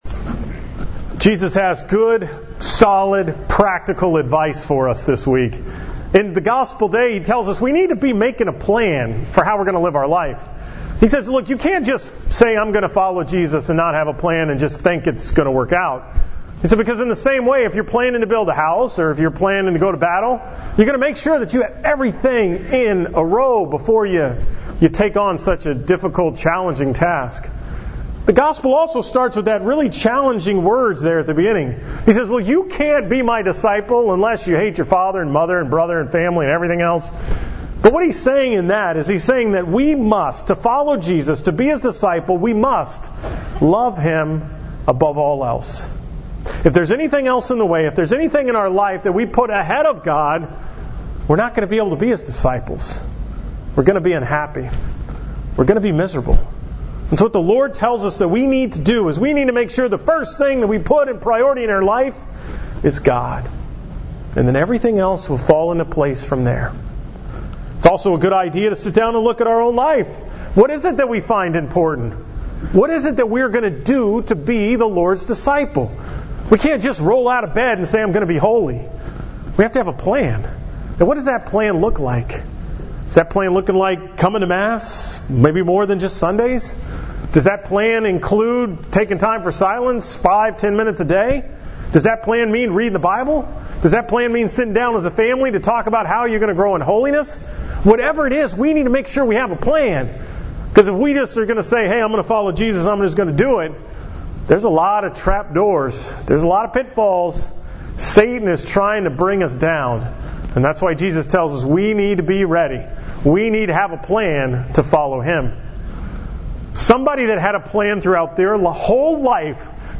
From the 5:30 pm Mass at Our Lady of Fatima